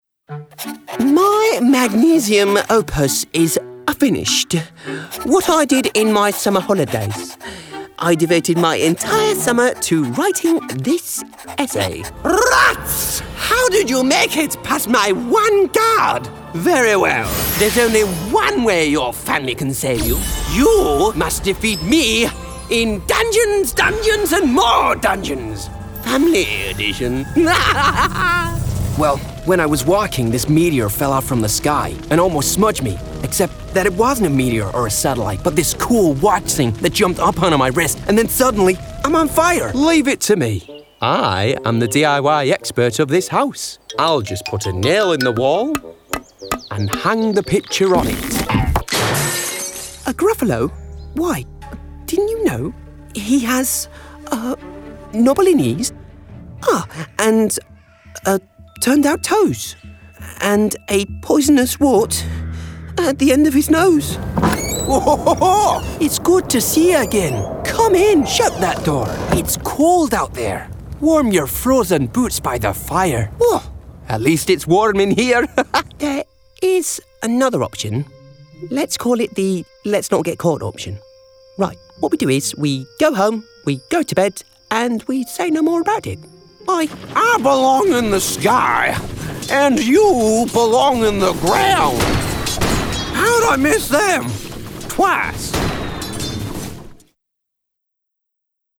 Animation Reel
• Native Accent: Teeside
• Home Studio
His native north-east is light and amicable, but he can plunge those vocal depths for villainy at a finger click.